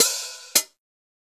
OPEN_HAT_THE_BATTLE.wav